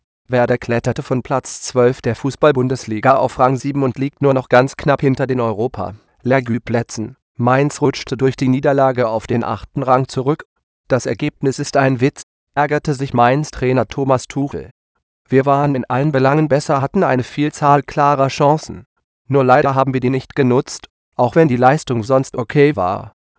Voice Demo
AT&T Natural Voices ™ Reiner 16k (German)